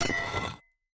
Minecraft Version Minecraft Version snapshot Latest Release | Latest Snapshot snapshot / assets / minecraft / sounds / item / axe / scrape2.ogg Compare With Compare With Latest Release | Latest Snapshot
scrape2.ogg